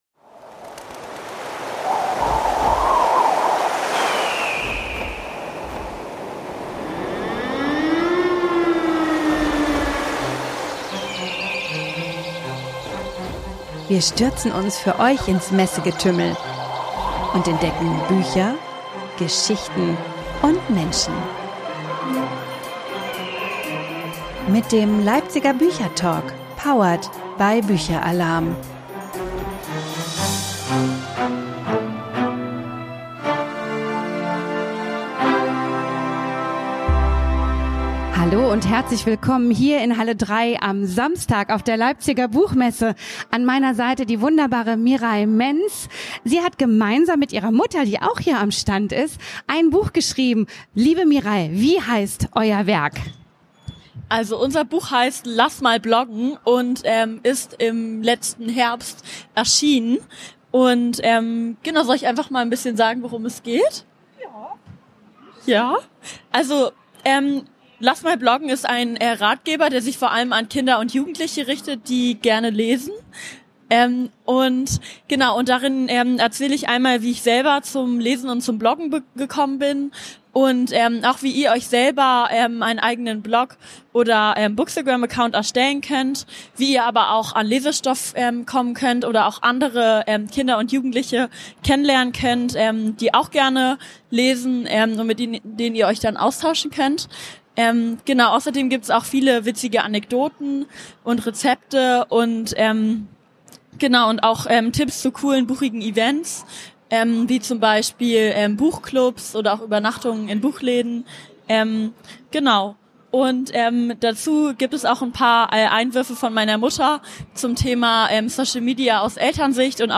Das dynamische Mutter/Tochter-Gespann ist bei uns zu Gast.